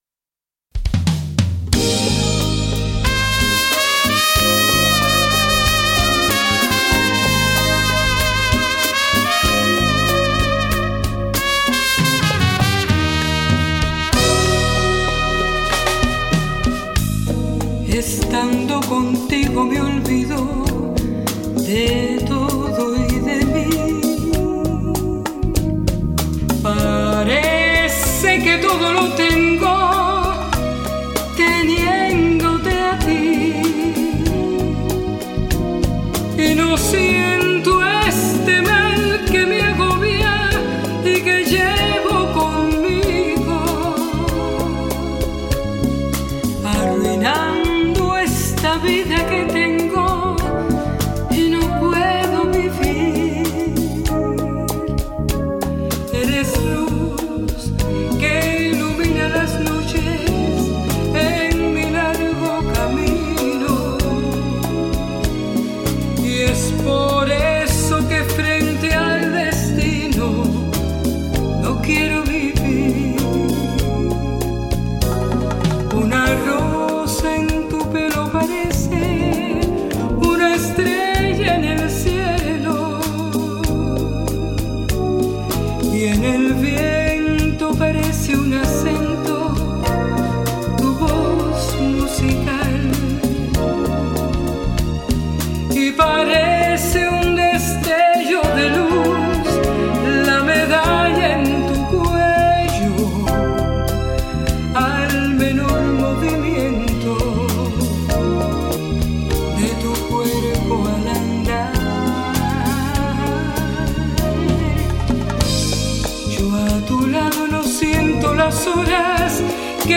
Žánr: Jazz/Blues
najkrajsie kubanske bolera